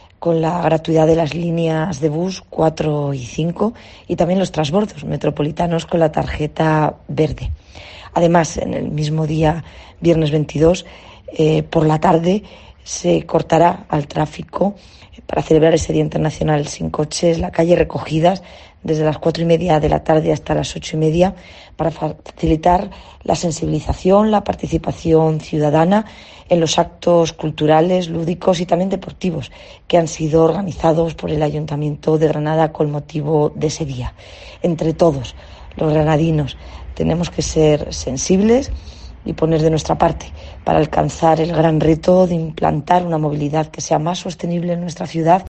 Marifrán Carazo, alcaldesa de Granada